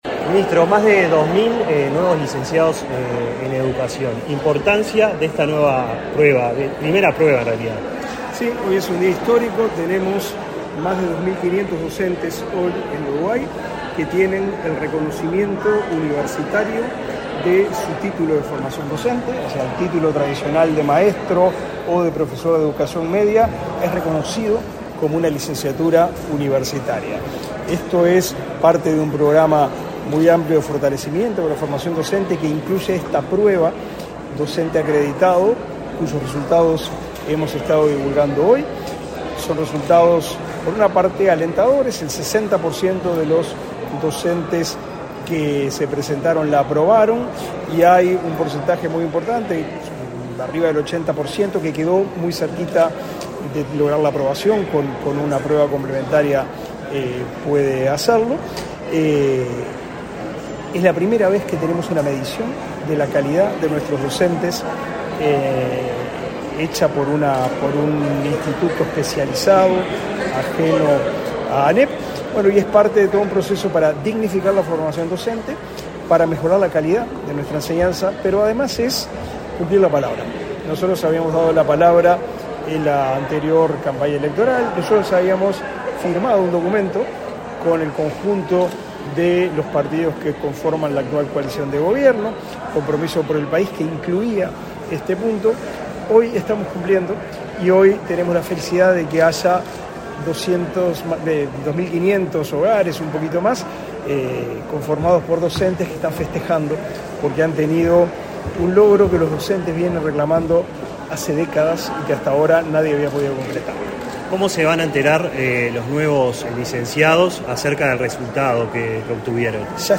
Declaraciones a la prensa del ministro del MEC, Pablo da Silveira
Tras el evento, el ministro Pablo da Silveira realizó declaraciones a la prensa.